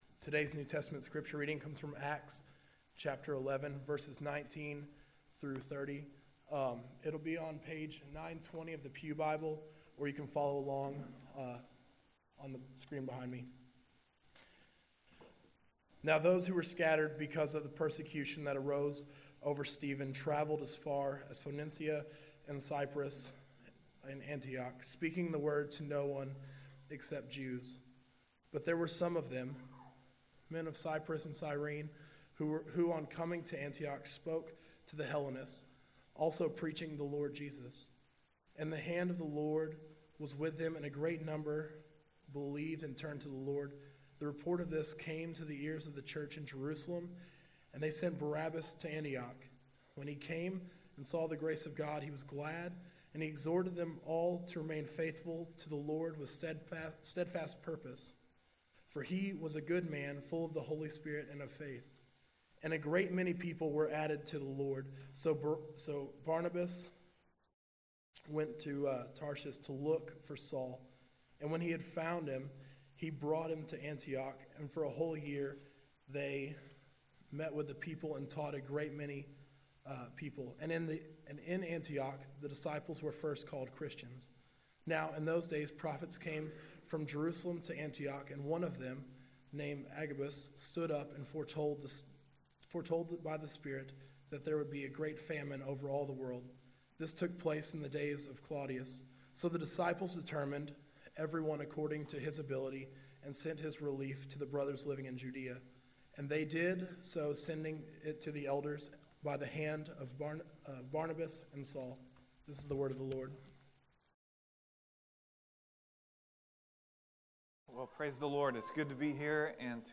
October 16, 2016 Morning Worship | Vine Street Baptist Church
After the sermon, everyone stood and sang Holy, Holy, Holy during a time of response and reflection